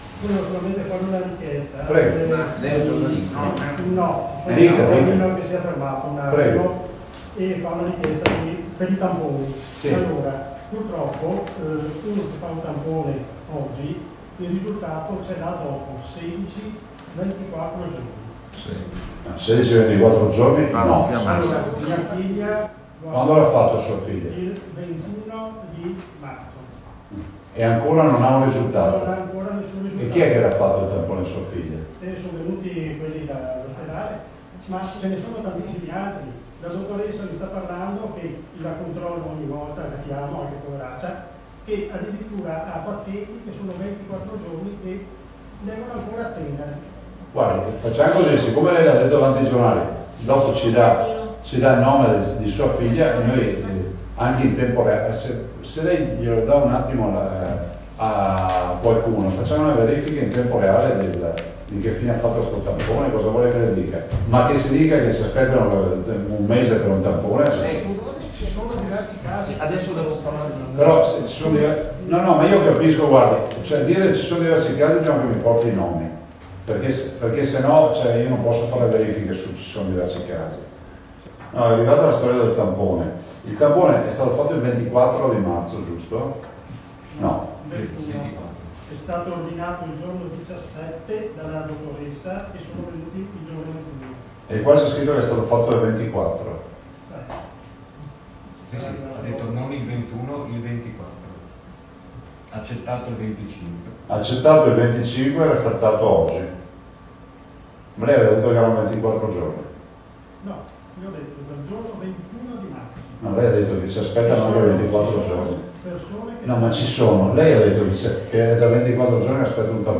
I PASSAGGI PRINCIPALI DELLA CONFERENZA STAMPA DI ZAIA OGGI
ZAIA-DOMANDA-E-RISPOSTA-TAMPONI.mp3